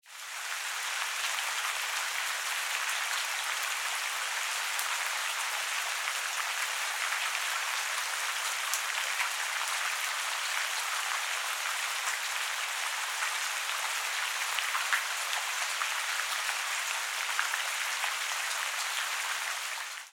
Spring Rainstorm Sounds for Relaxation and Meditation MP3
What started as an attempt to record a light early Spring rain shower turned into a magical experience. The rain began innocently enough, but then half way through the recording, the rain gradually began picking up, until finaly the skies just decided to unload, releasing sheets of water so dense that it was no longer possible to discern the sound of individual rain drops! While spectacular, the deluge was rather short-lived, and soon the rain reverted back to the gentle drizzle of the early part of the recording session. This soundtrack seems to tell a story, with a beginning, a build-up, a climax, and a resolution.
Spring-Rainstorm-sample.mp3